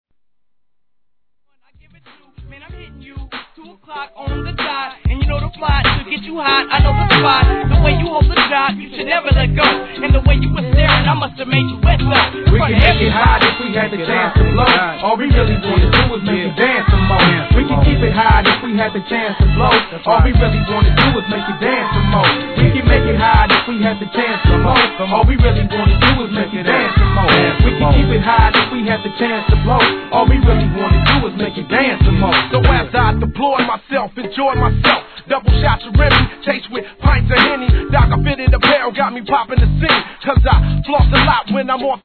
G-RAP/WEST COAST/SOUTH
哀愁漂うミディアム好FUNK